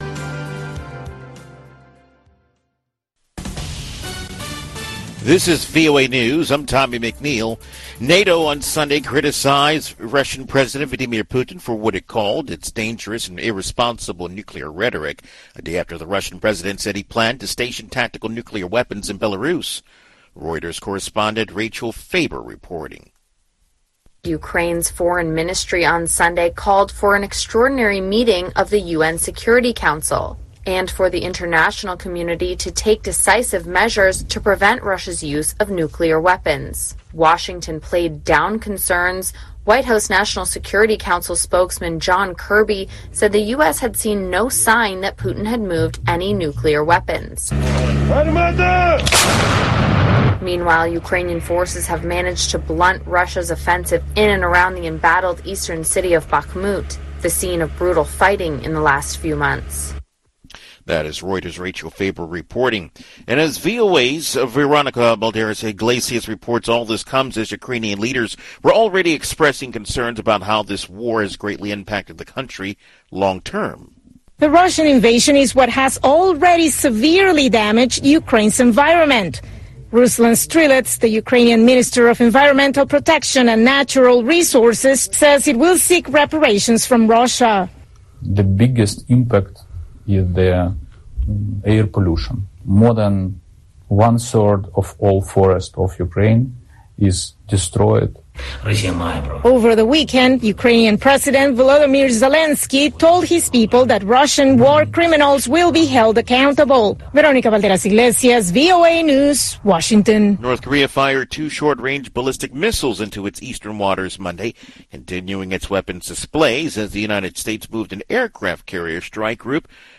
Two-Minute Newscast